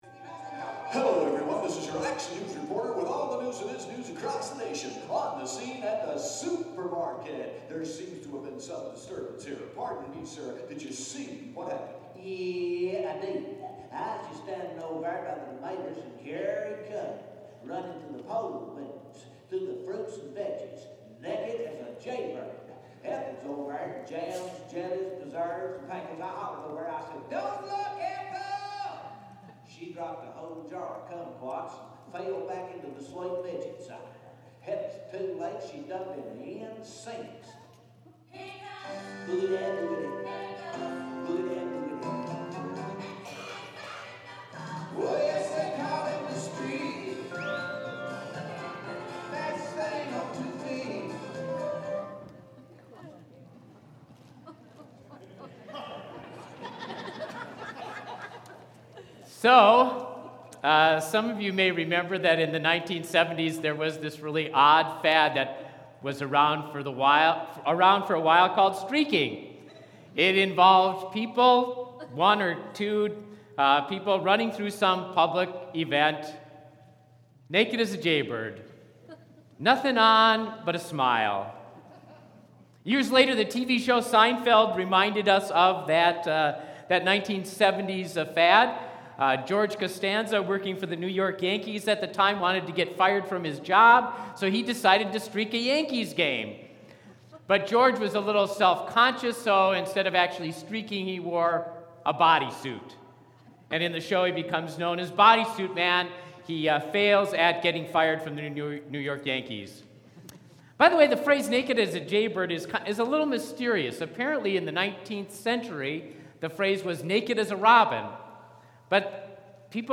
Sermon preached November 8, 2015